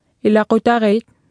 Nedenfor kan du afprøve tekst-til-tale-system Martha.
Talesyntese Martha til computer eller mobiltelefon